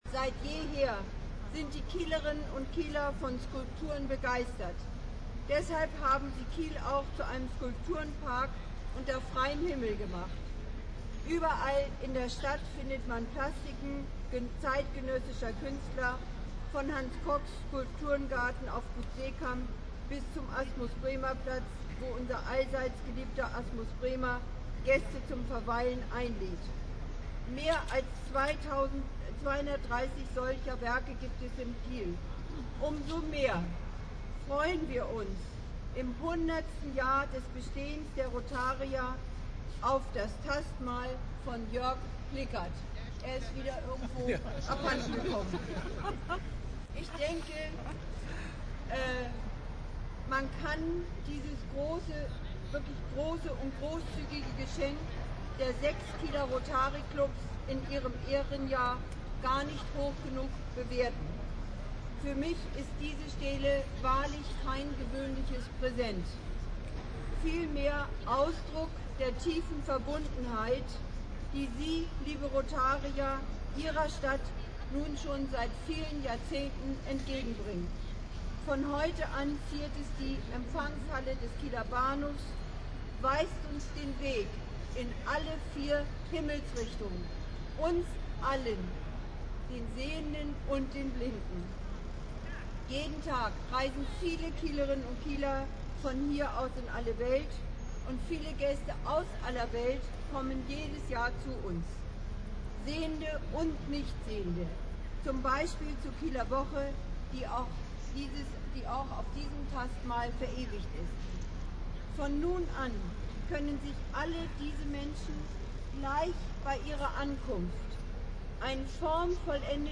Angelika Volquartz (Oberbürgermeisterin von Kiel)